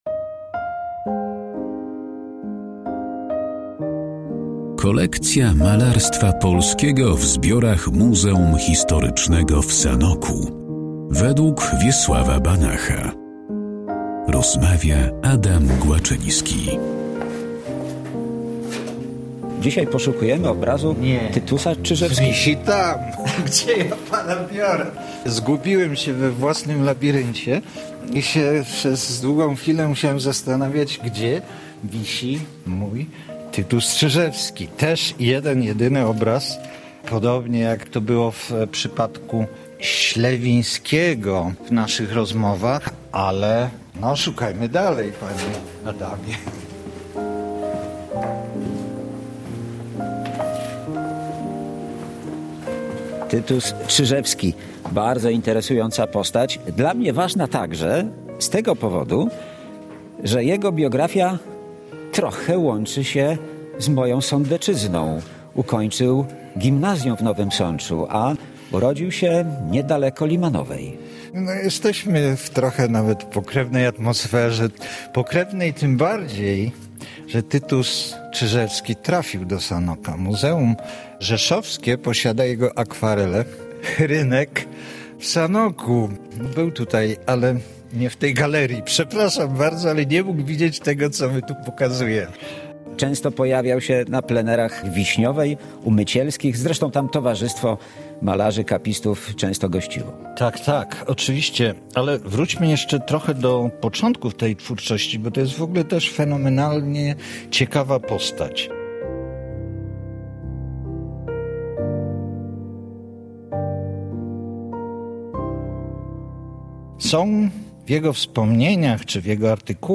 O twórczości Tytusa Czyżewskiego i jego obrazie znajdującym się w Muzeum Historycznym w Sanoku rozmawiają: